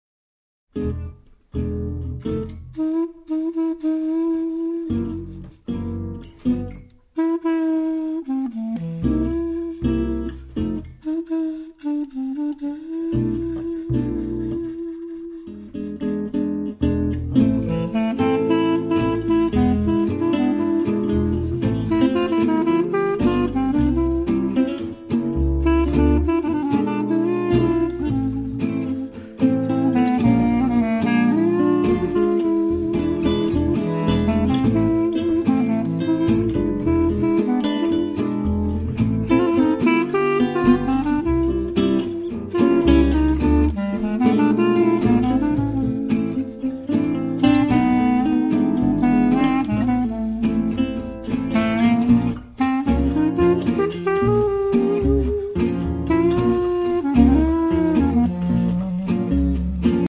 Clarinets
Guitar
Double Bass